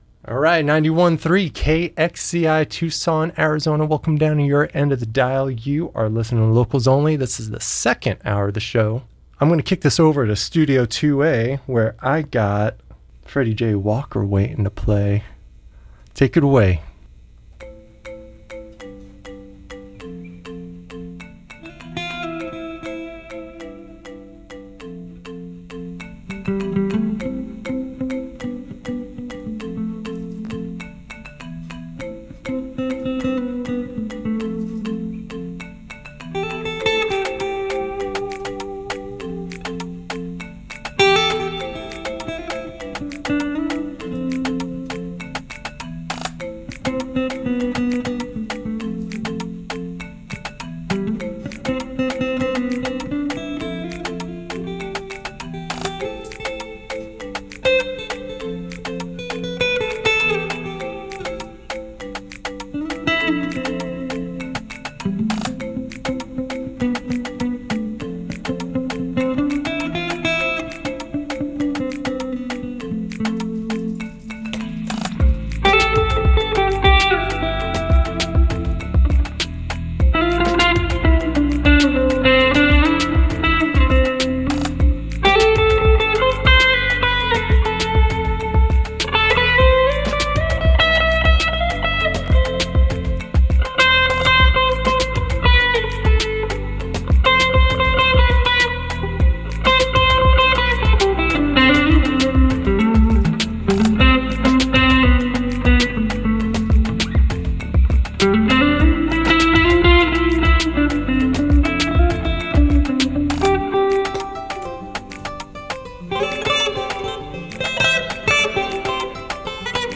Listen to the live performance + interview here!
live in Studio 2A
Full session recording
Live Music
multi-instrumentalist